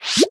Minecraft Version Minecraft Version 25w18a Latest Release | Latest Snapshot 25w18a / assets / minecraft / sounds / mob / pufferfish / blow_up1.ogg Compare With Compare With Latest Release | Latest Snapshot
blow_up1.ogg